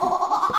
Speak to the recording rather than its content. Les sons ont été découpés en morceaux exploitables. 2017-04-10 17:58:57 +02:00 102 KiB Raw History